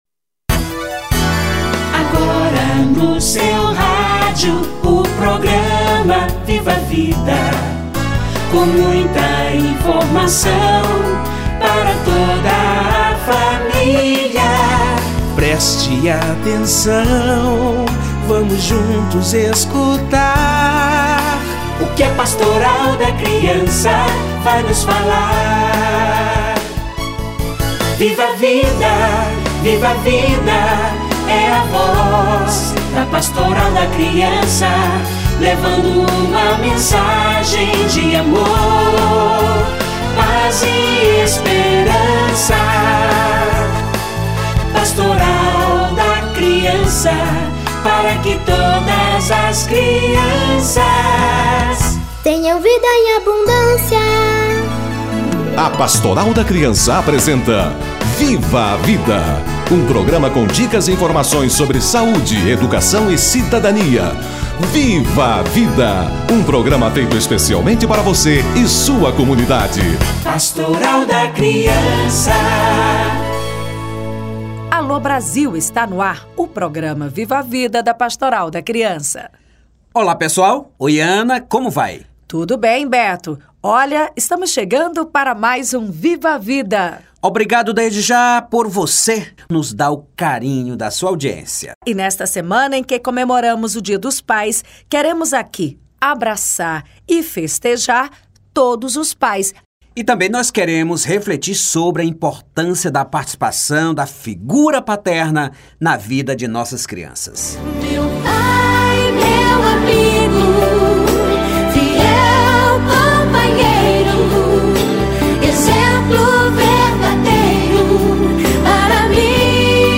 Dia dos Pais - Entrevista